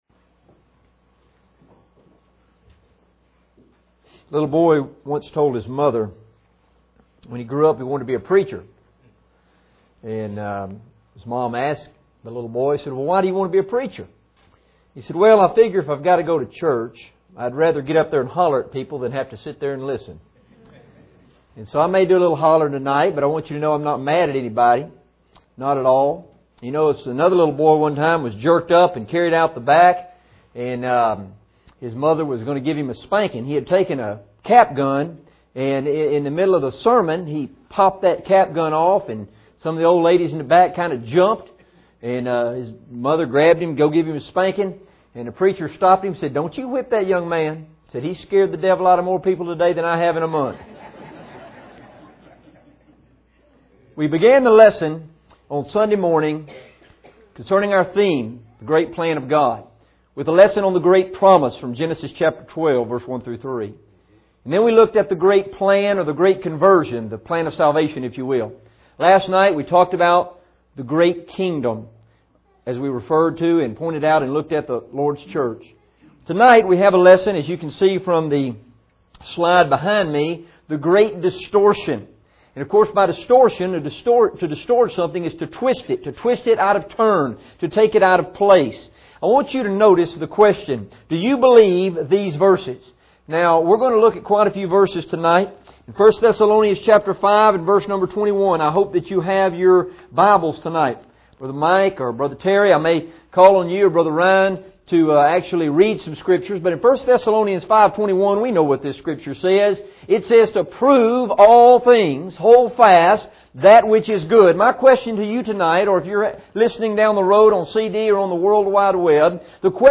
Part of our 2011 Fall Meeting